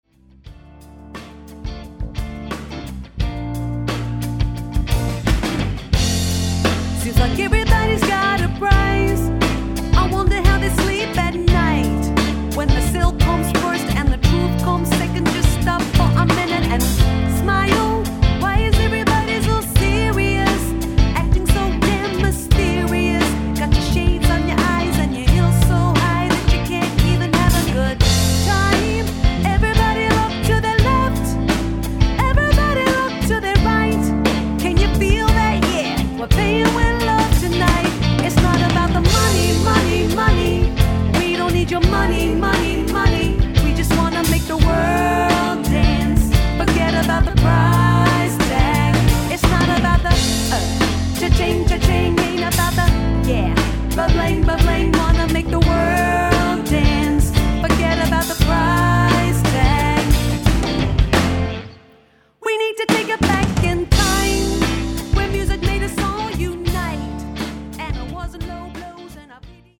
Melbourne Wedding Party Cover Band Hire